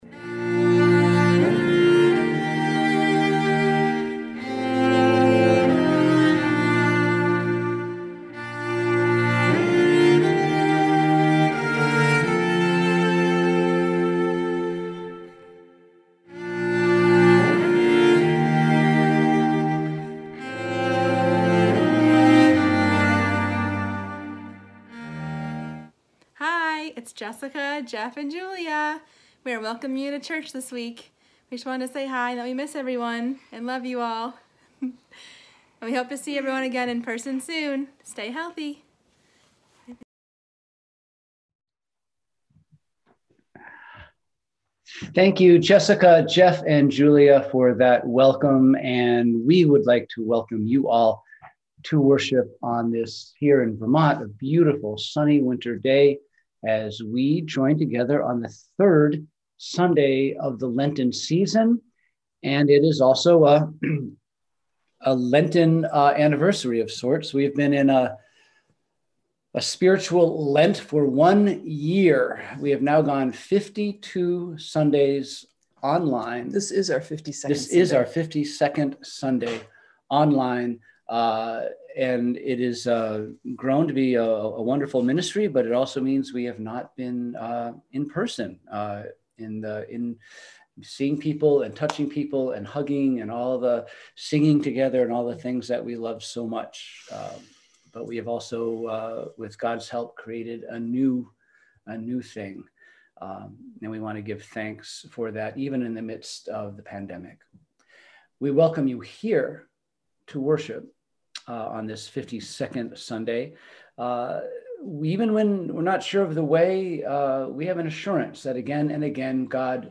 We held virtual worship on Sunday, March 7, 2021 at 10AM!